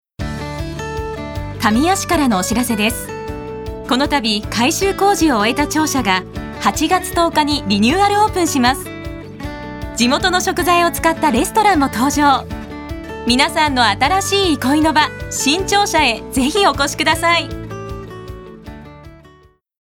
Voice／メゾソプラノ
ボイスサンプル